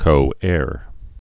(kō-âr)